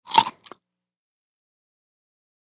DINING - KITCHENS & EATING CARROT: INT: Single short bite into a carrot.